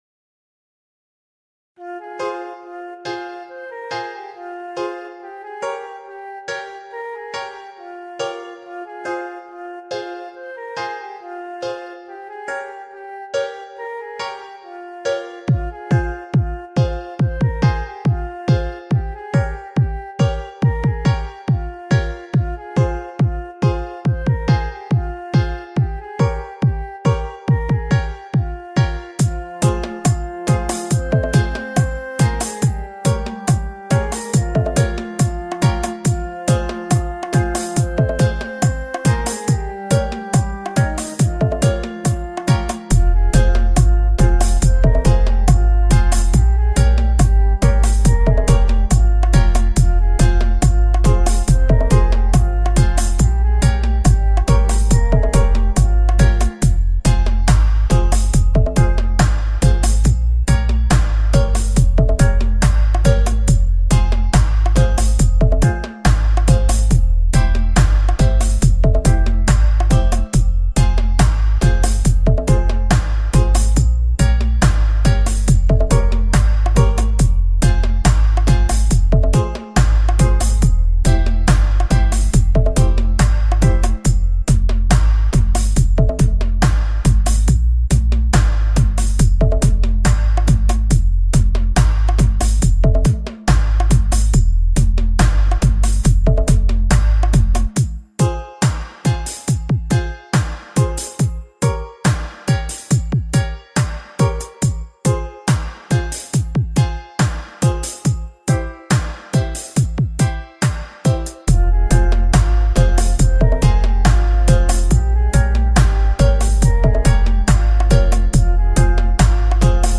Dubplate